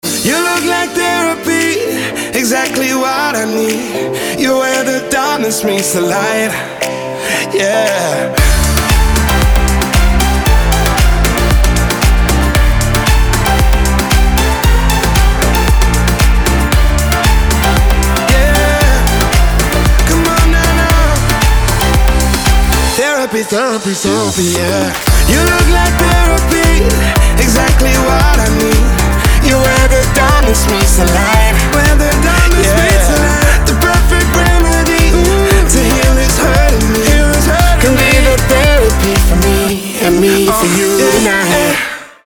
dance
EDM
house